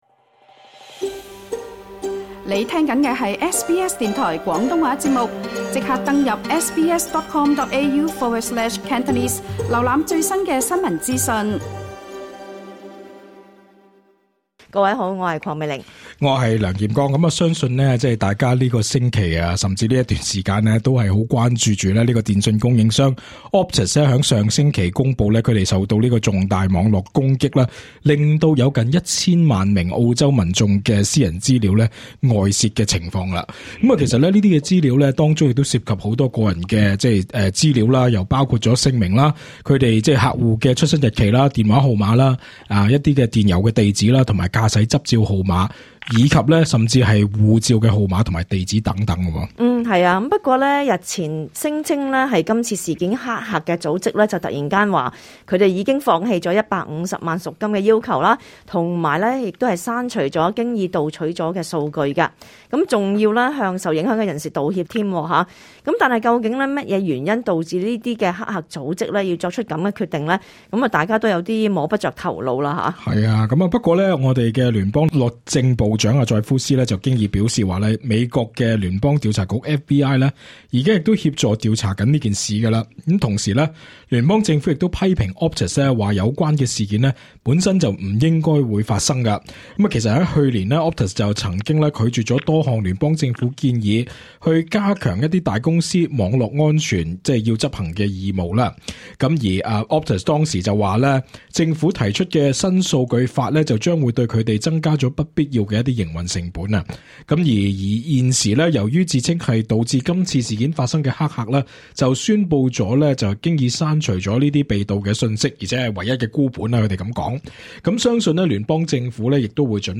*本節目內嘉賓及聽眾意見並不代表本台立場，而所提供的資訊亦只可以用作參考，個別實際情況需要親自向有關方面查詢為準。